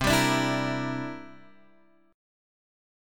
C 9th